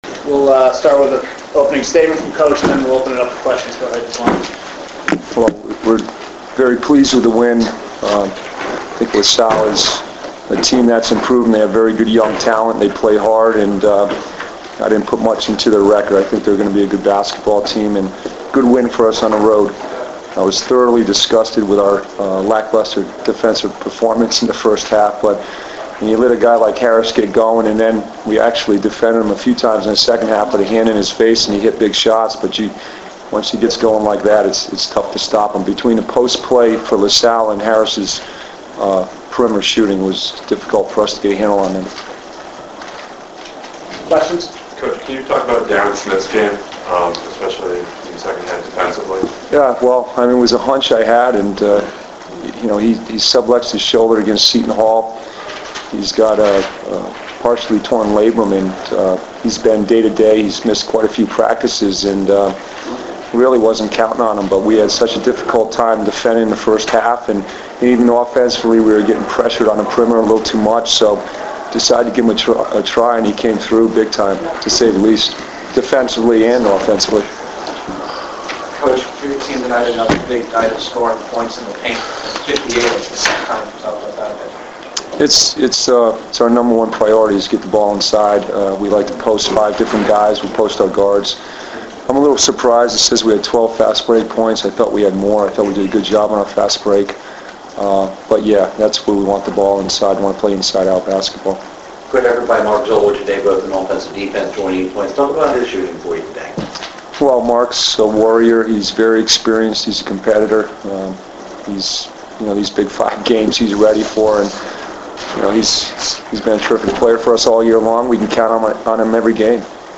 Tom Gola Arena – Philadelphia, PA Boxscore
Postgame audio: